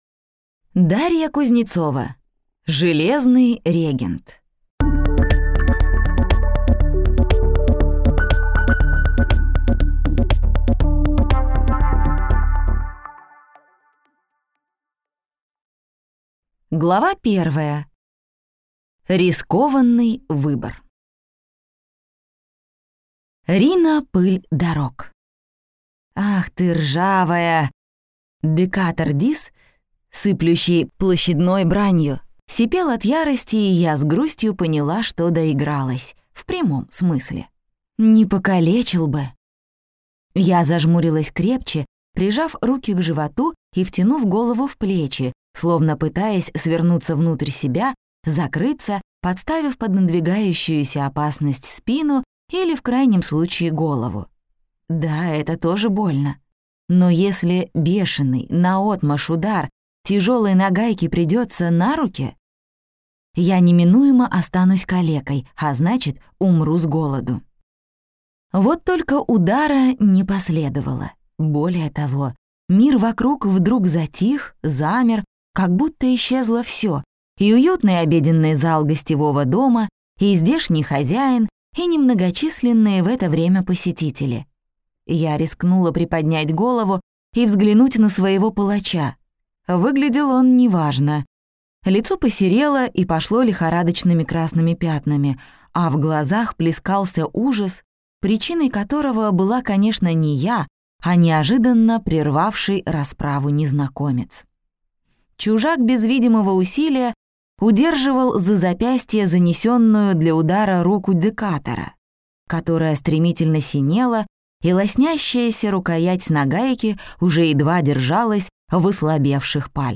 Аудиокнига Железный регент | Библиотека аудиокниг
Прослушать и бесплатно скачать фрагмент аудиокниги